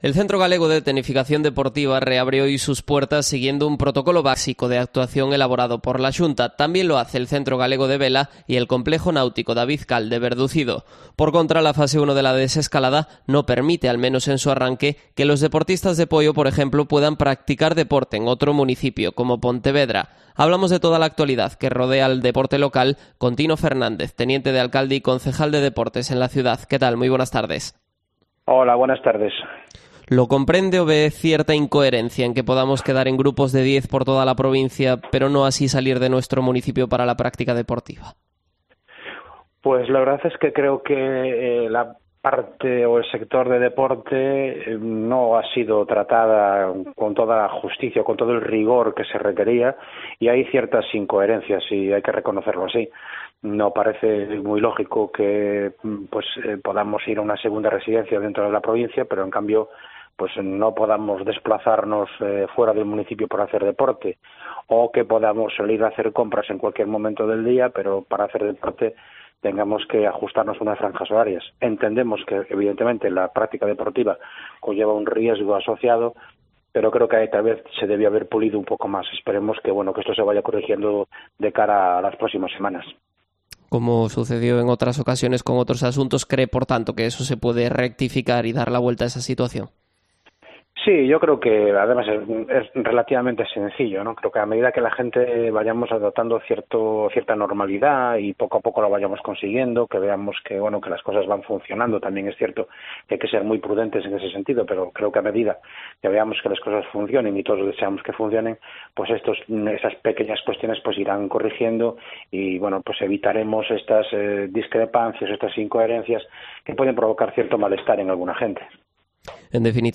Entrevista a Tino Fernández, concejal de Deportes de Pontevedra